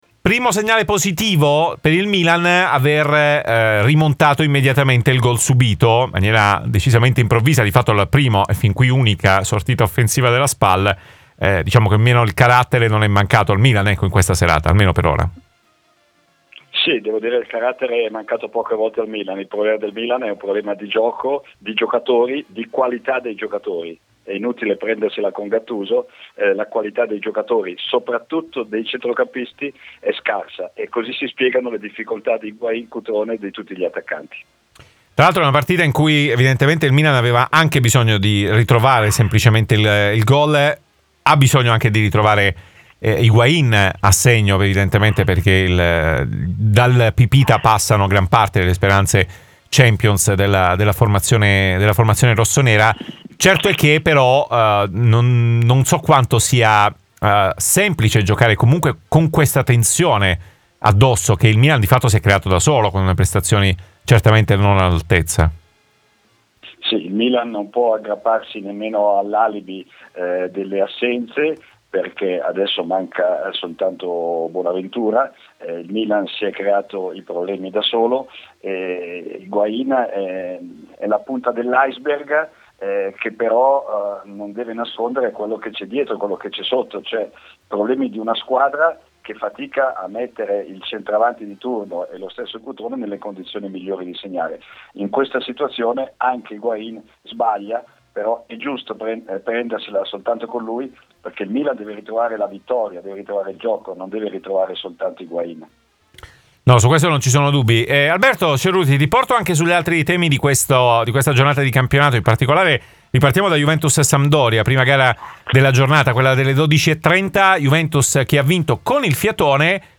in diretta nel 'Live Show' di RMC Sport
a commento della giornata di campionato. In studio